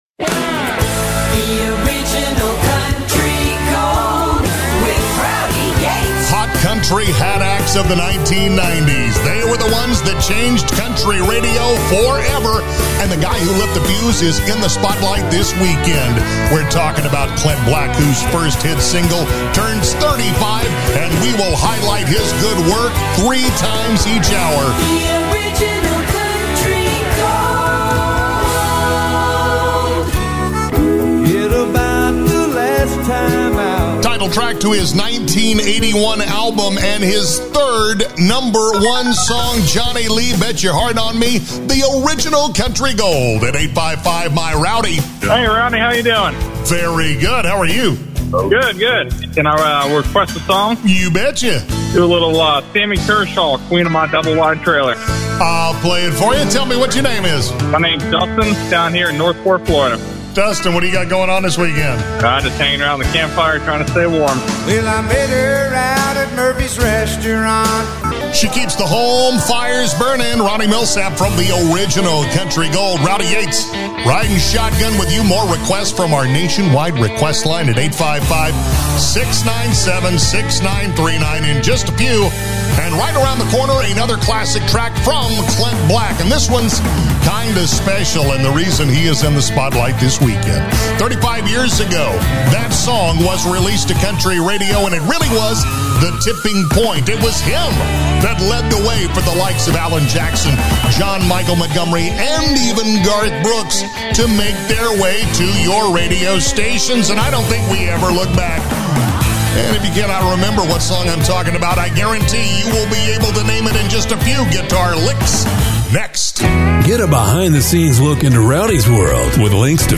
Format Country
The Original Country Gold Demo